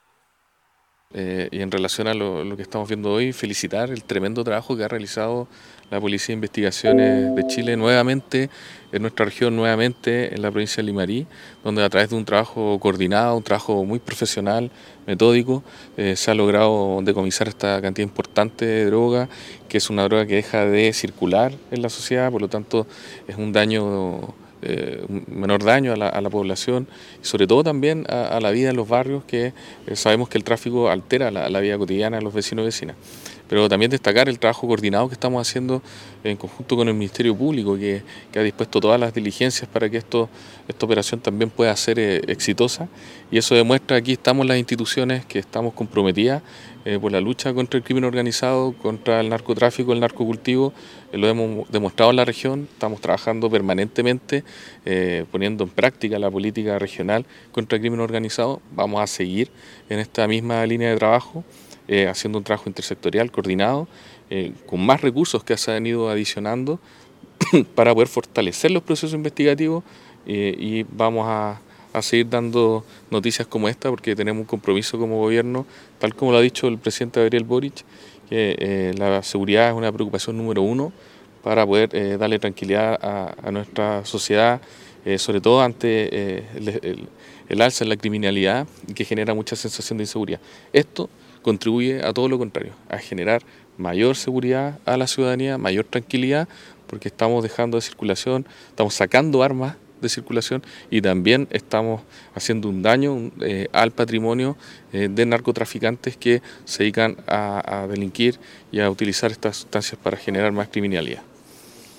Esta multimillonaria incautación y todas sus evidencias fueron exhibidas a las autoridades y medios de comunicación en el Estadio Municipal Diaguita de Ovalle.